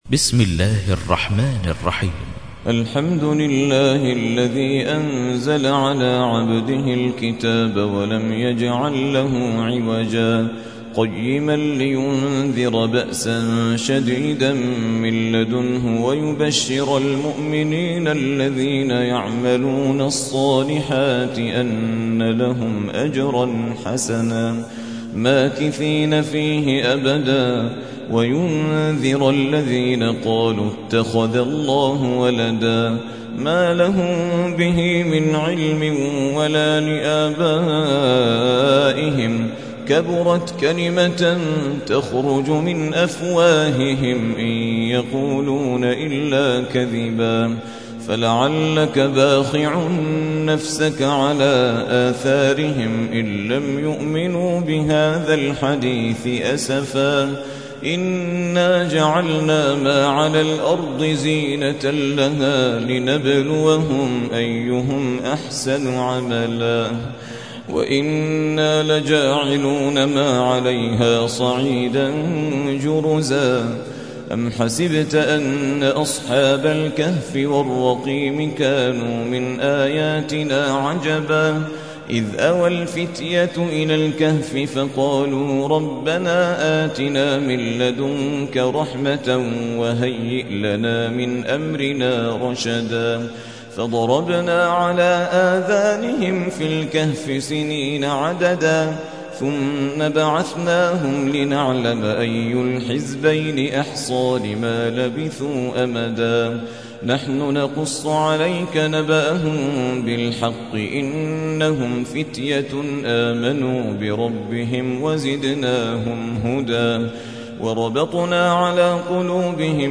موقع يا حسين : القرآن الكريم 18.